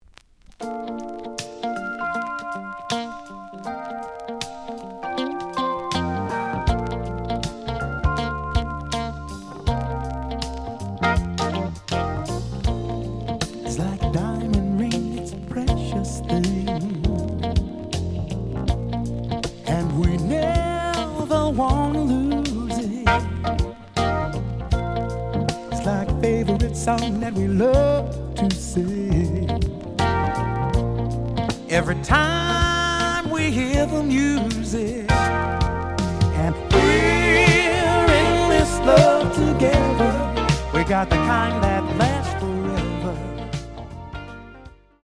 The audio sample is recorded from the actual item.
●Format: 7 inch
●Genre: Jazz Funk / Soul Jazz